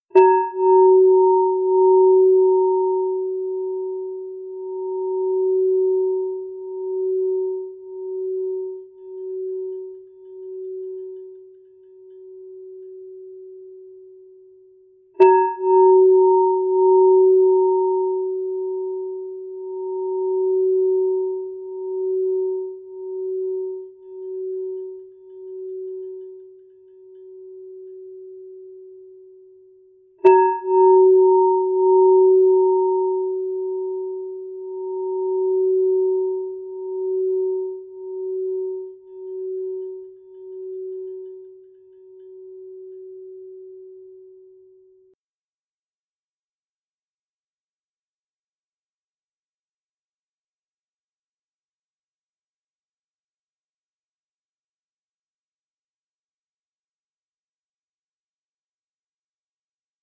Gong struck 3 Times (Mediawalla, Mangala Media)
Gong Sound